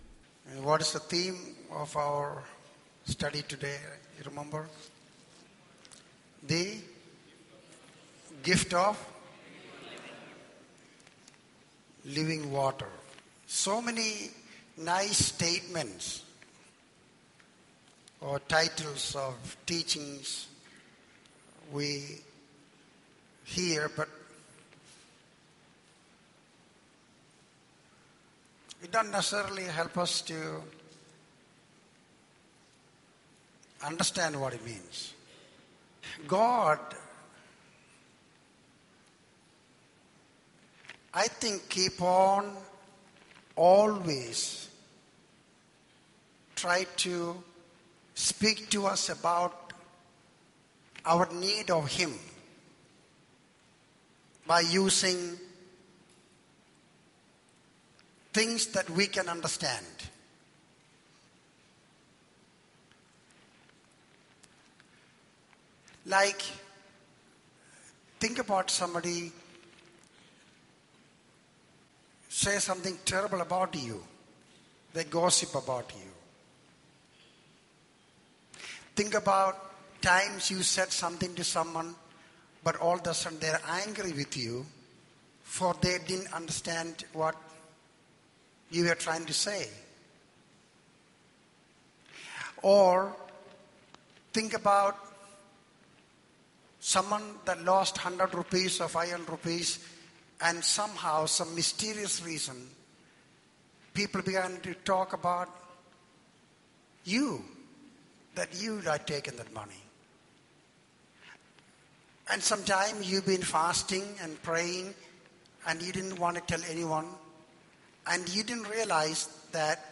In this sermon, the preacher discusses the concept of the gift of living water. He emphasizes that God often speaks to us through relatable experiences and situations in our lives.